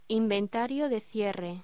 Locución: Inventario de cierre
voz